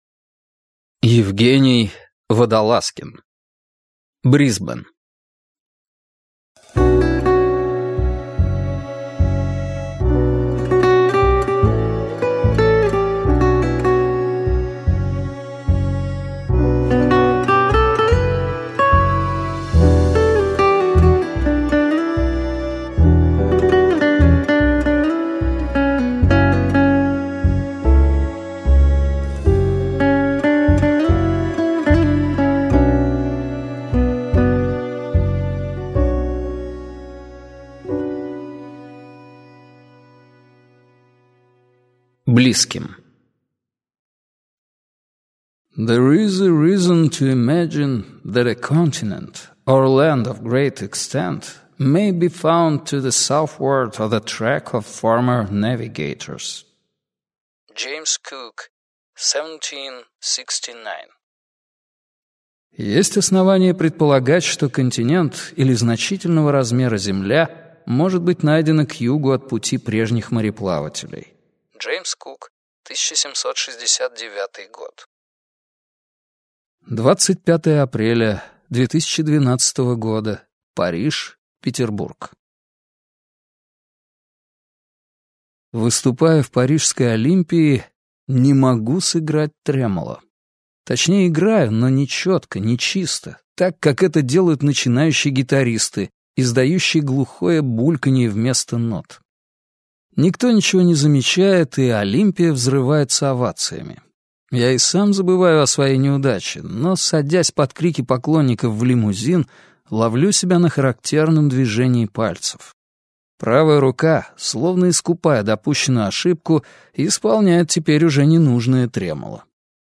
Аудиокнига Брисбен | Библиотека аудиокниг